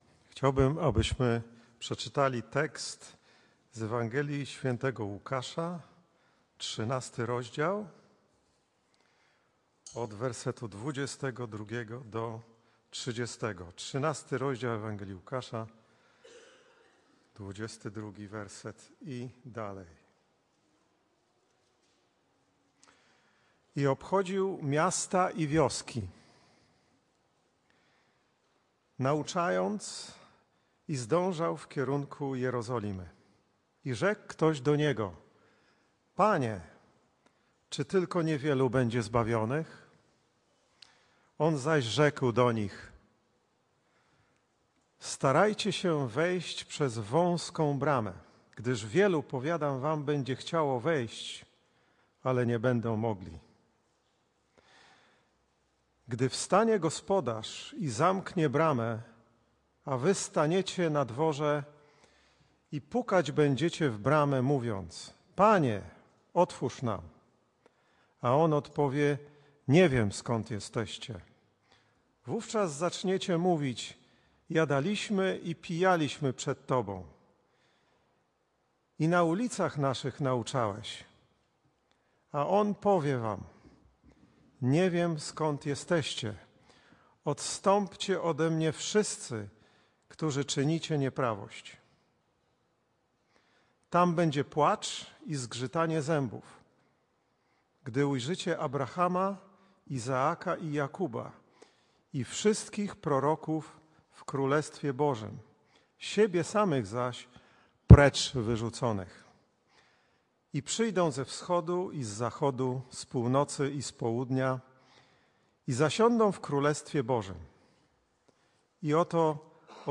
Kazanie